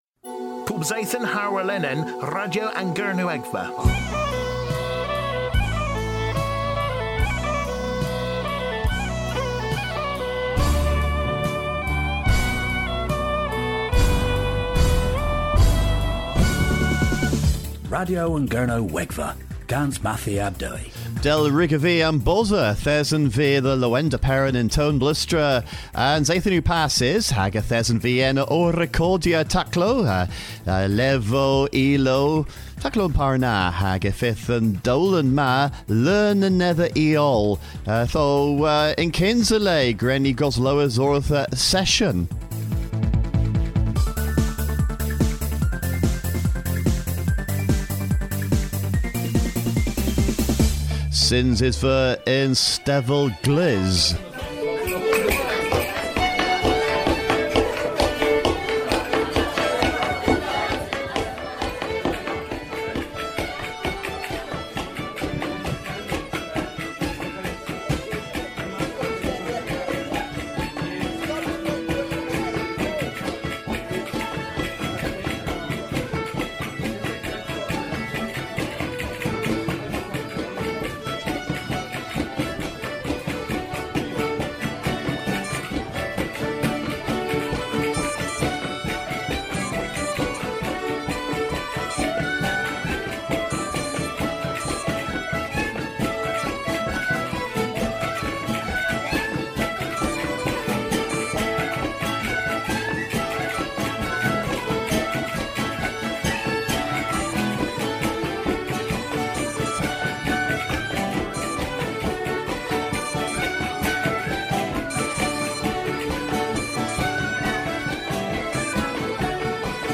Myns an dowlen a dheu dhiworth Ostel Atlantek yn Tewyn Bleustri.